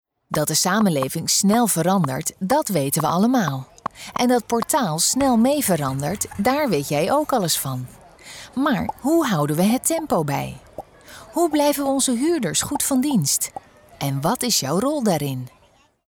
Sprecher
Kommerziell, Zuverlässig, Freundlich, Warm, Corporate
Unternehmensvideo